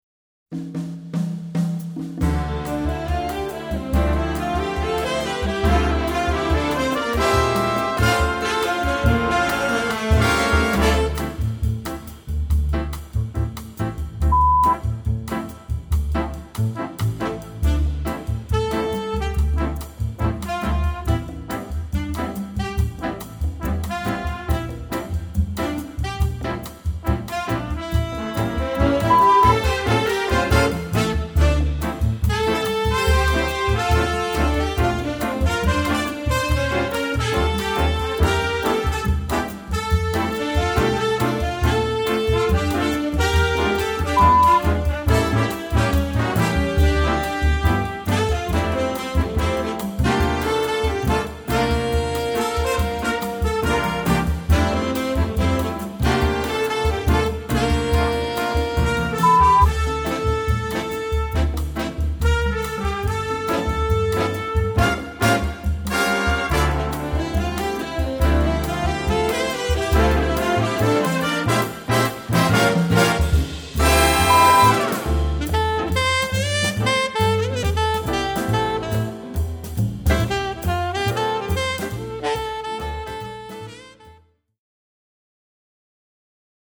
Répertoire pour Jazz band - Jazz Band